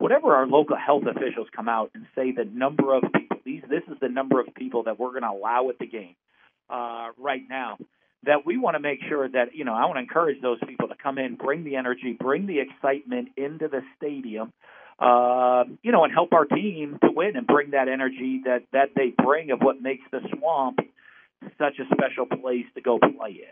During a press conference today, Dan apologizes and backtracks his comments from the weekend.